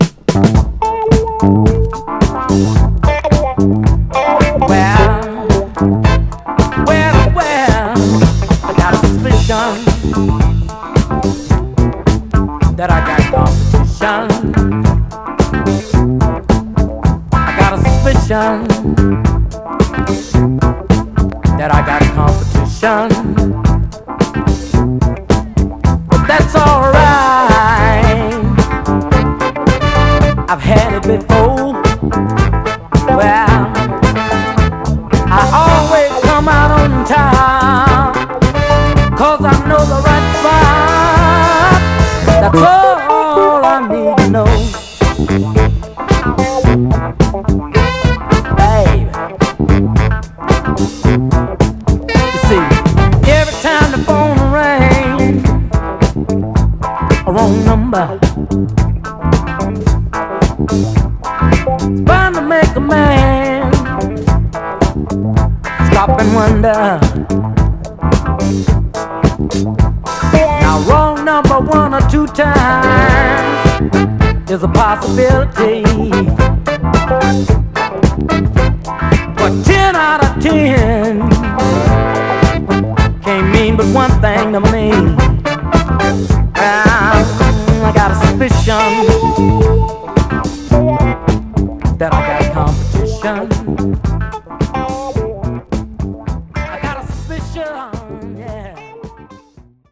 盤は薄いスレ、細かいヘアーラインキズ、B面ランアウトに目立つスレ箇所ありますが、両面共にプレイ概ね良好です。
※試聴音源は実際にお送りする商品から録音したものです※